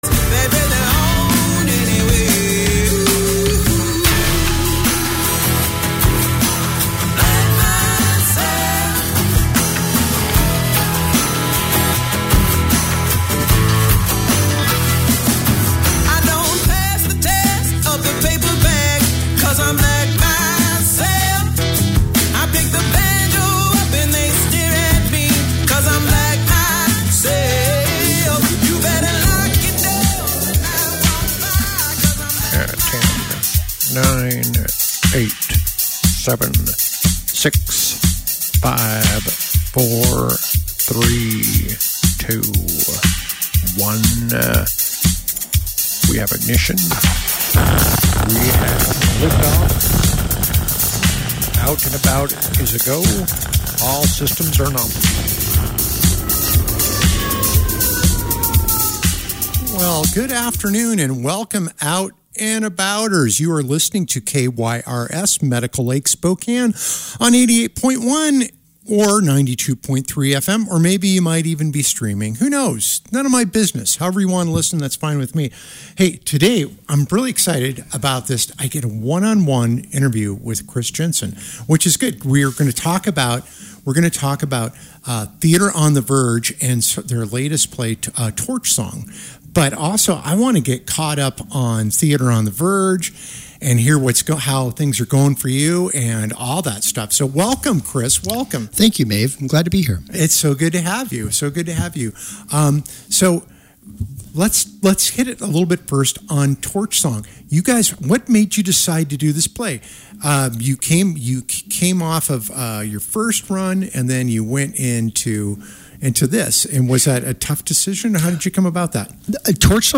Tune into Out and About every Tuesday at 4 pm on KYRS at 88.1 and 92.3 fm.